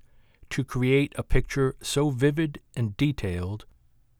Rode NT1-A mic with pop shield
I have what I believe is called a plosive in what is to be my first job narrating.
Having said that I don’t think you have a plosive thud on the “p” of “picture”,
It’s the “a” immediately before which has a high-pitched (~9.5kHz) rattle.